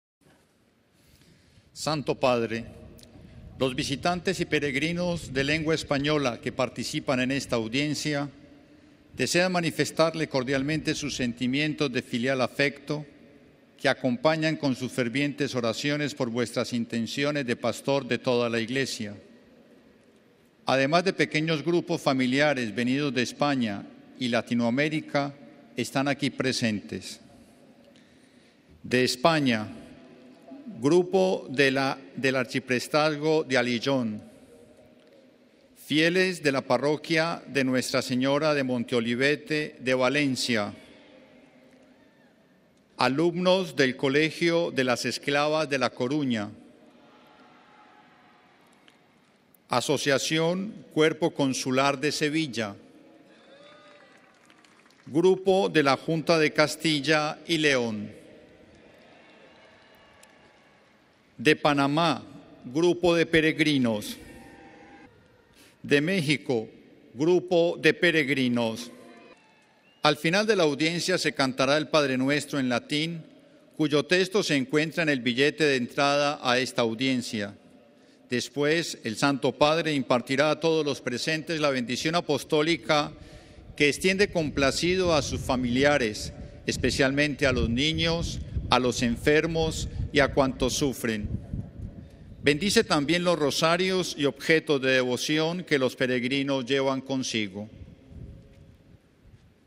Numerosos grupos de peregrinos del Continente Americano y de España fueron saludados por el Papa, escuchemos la presentación realizada por un Oficial de la Curia Romana (Audio): RealAudio